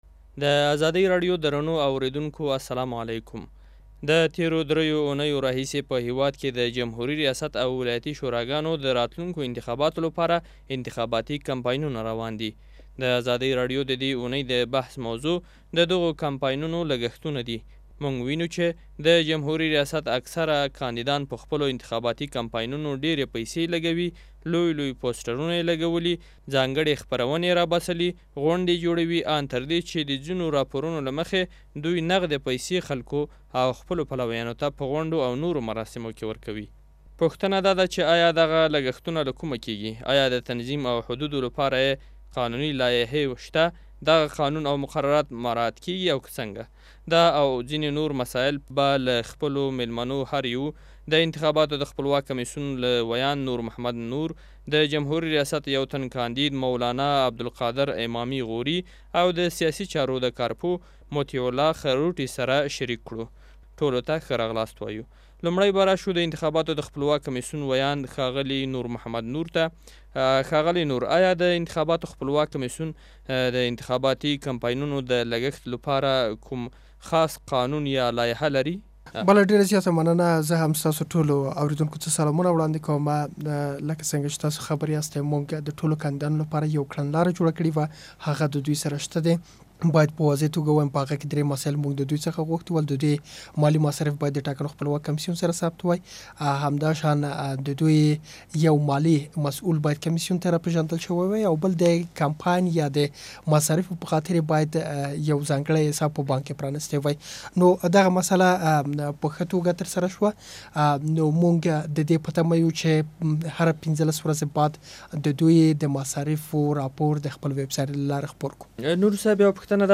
د انتخاباتي مبارزو لګښتونه څومره، او له کومه ؟ بحث واورﺉ